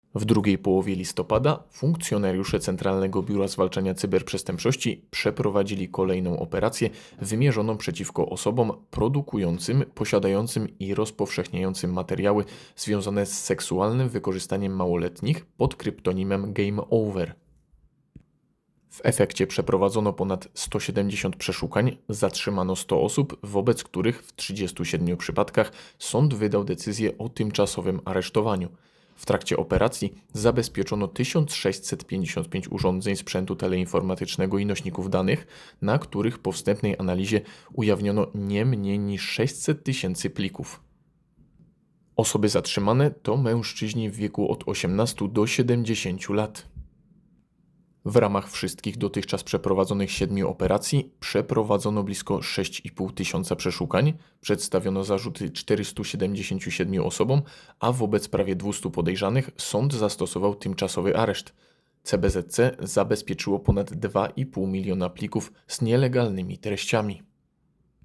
Komunikat-SetkaGAMEOVER.mp3